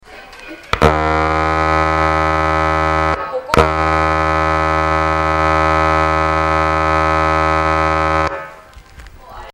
艦橋で鳴らせられる汽笛 （9秒） 艦橋から函館ドックを臨む。
kiteki.mp3